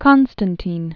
(kŏnstən-tēn, -tīn) 1868-1923.